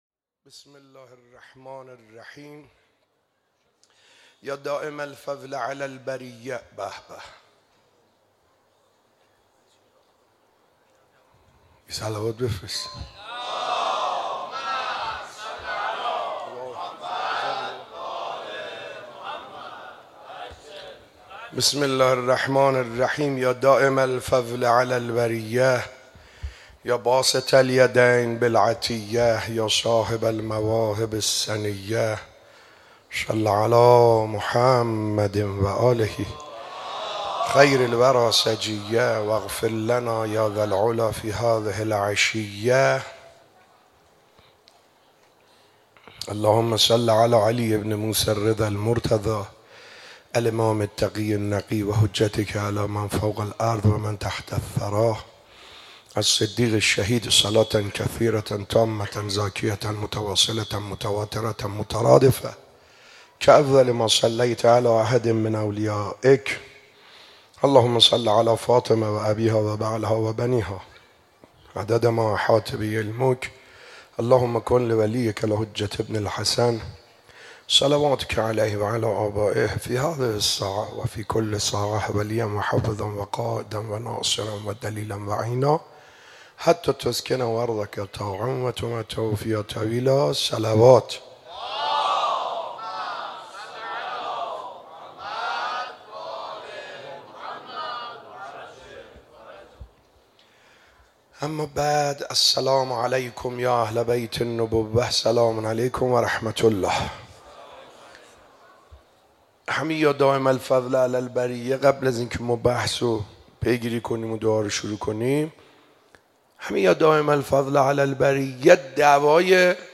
شب 25 ماه مبارک رمضان 95_صحبت